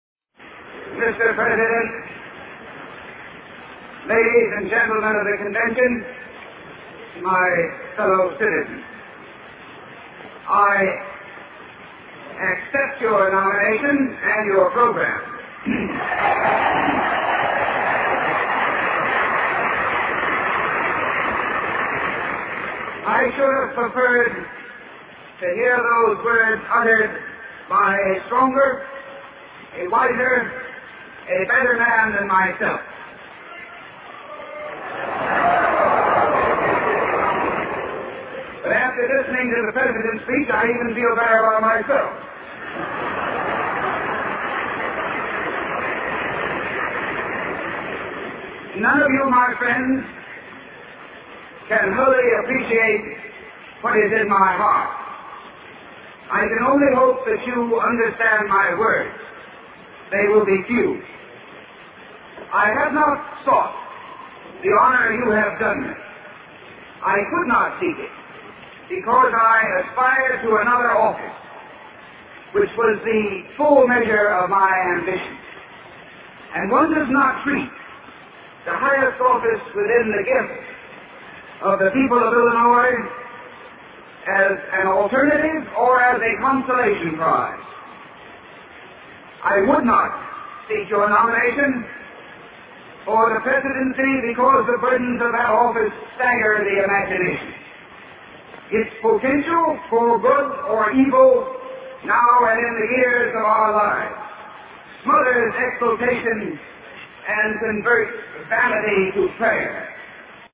经典名人英语演讲(中英对照):Presidential Nomination Acceptance Speech 1